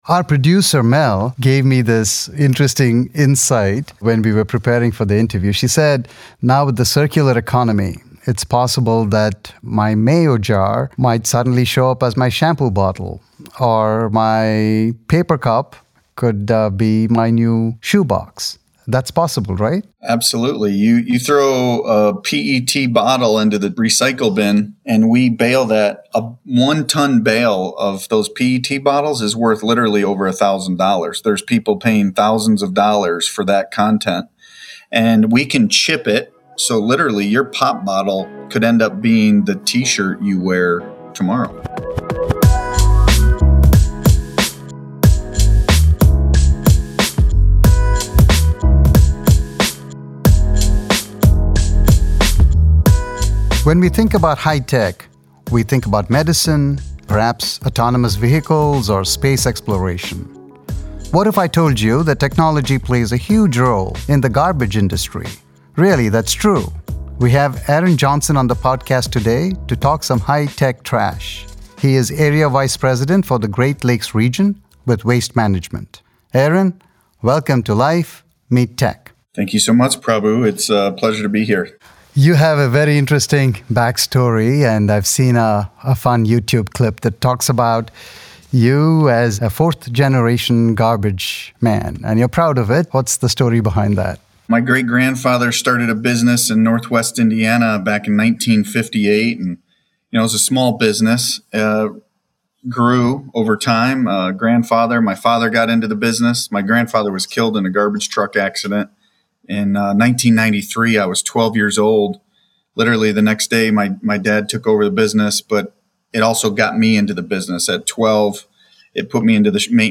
A conversation about living our lives with technology.